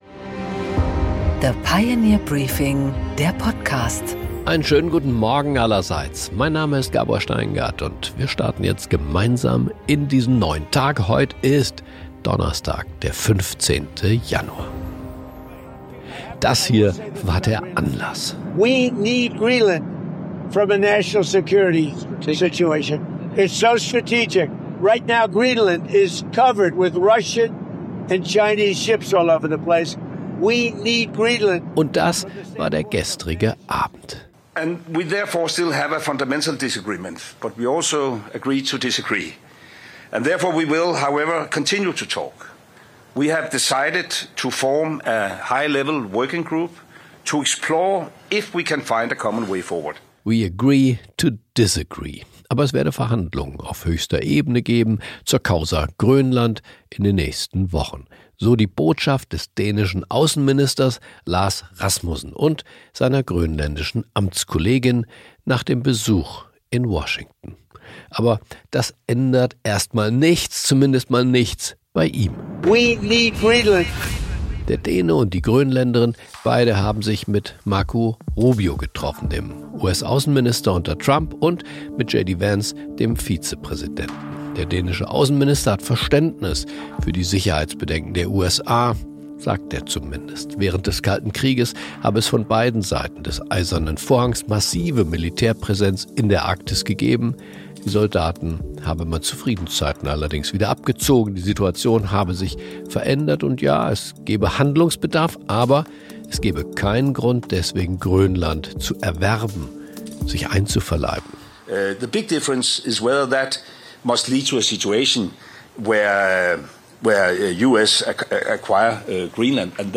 Gabor Steingart präsentiert das Pioneer Briefing.
Im Gespräch: Prof. Hans‑Werner Sinn, Ökonom, erläutert im Gespräch mit Gabor Steingart seine Sicht auf die US-Inflation, den politischen Druck auf die Notenbank und was weitere Zinssenkungen bedeuten würden.